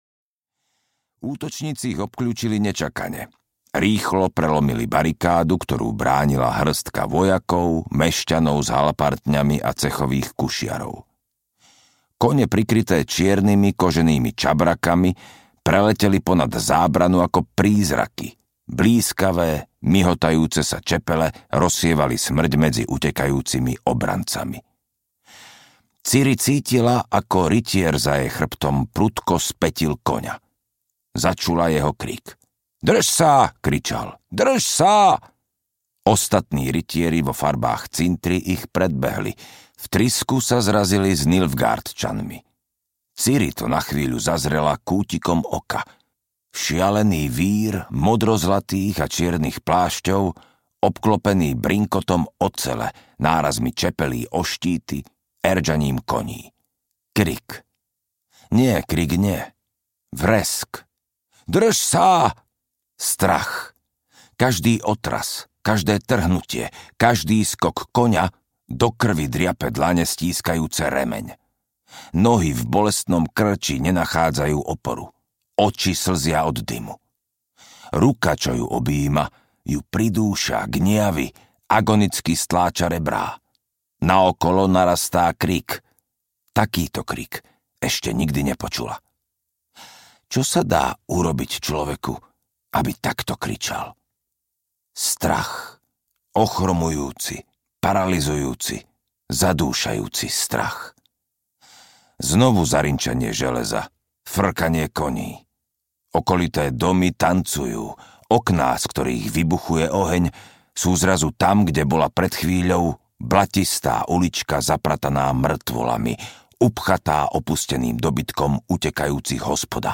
Zaklínač III: Krv elfov audiokniha
Ukázka z knihy